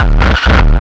HD BD 16  -R.wav